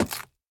Minecraft Version Minecraft Version snapshot Latest Release | Latest Snapshot snapshot / assets / minecraft / sounds / item / axe / strip3.ogg Compare With Compare With Latest Release | Latest Snapshot